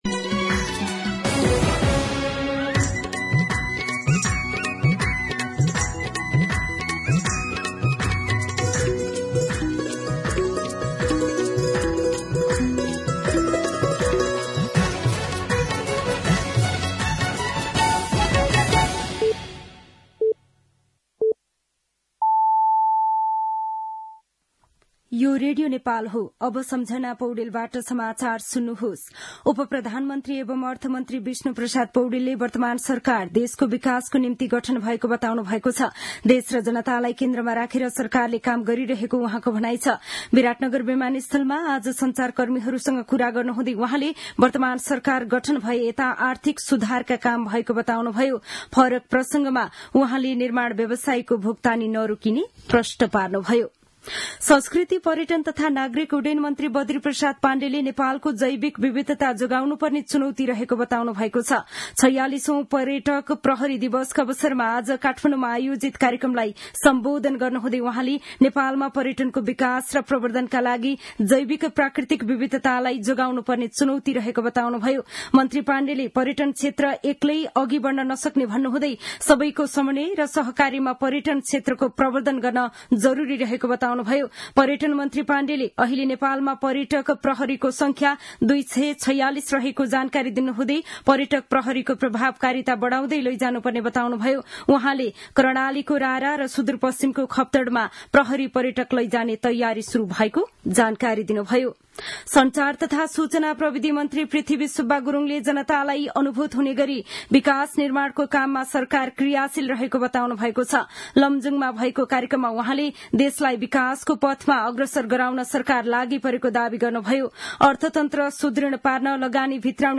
दिउँसो ४ बजेको नेपाली समाचार : १३ माघ , २०८१